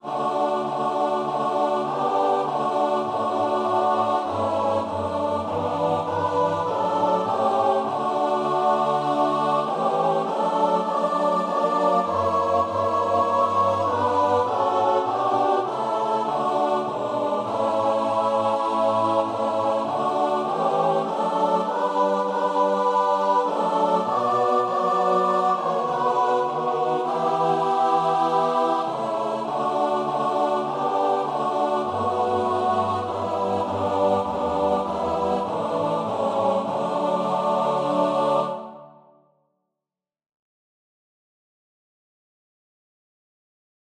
Free Sheet music for Choir (SATB)
4/4 (View more 4/4 Music)
Choir  (View more Easy Choir Music)
Classical (View more Classical Choir Music)